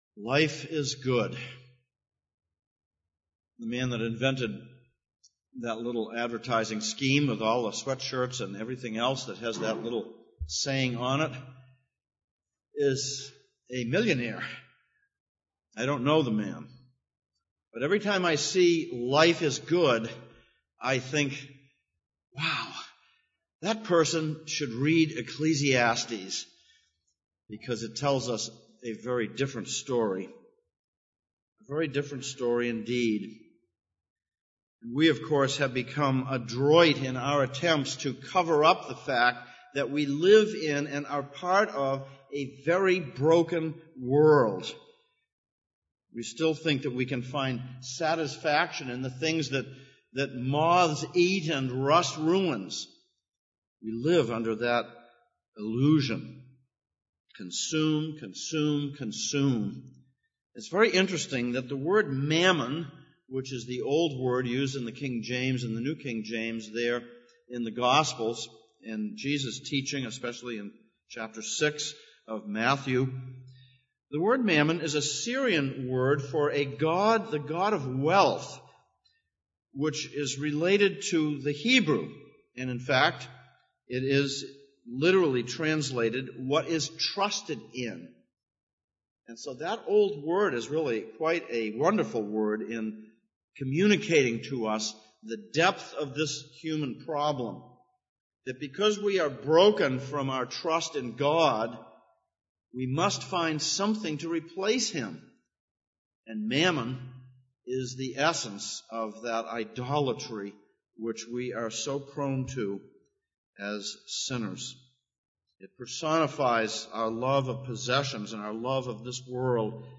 Series: Exposition of Ecclesiastes Passage: Ecclesiastes 5:8-6:7, 1 Timothy 6:1-10 Service Type: Sunday Morning